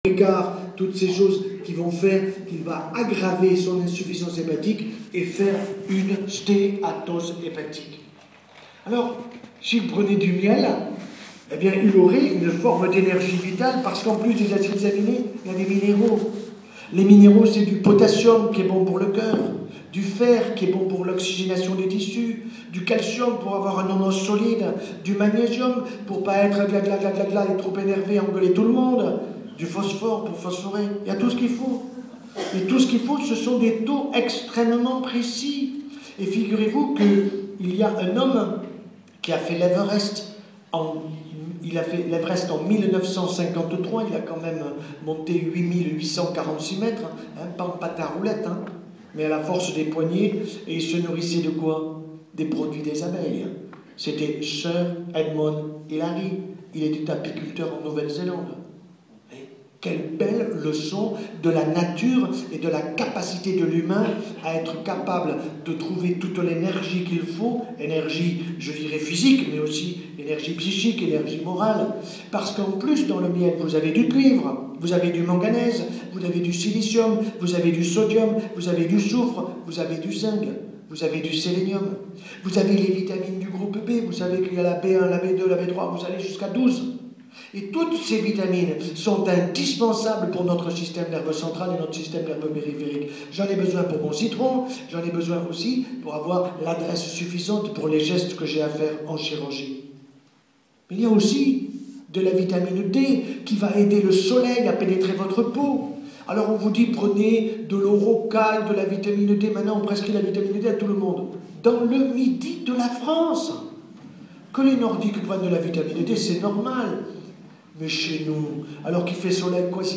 J'ai assisté personnellement à la conférence du Pr H Joyeux à Montpellier ,conférence donnée dans la belle salle antique , Petrarque .
Je mettrai encore des morceaux de cette conférence enregistrée avec mon iphone en plusieurs morceaux .
Conférence du Pr H.Joyeux Montpellier 16 Mai 2011 Sinon vous trouverez sur youtube plein de conférences données par cet Eminent Professeur cancérologue,Chirurgien du système gastrique au CHU de Montpellier .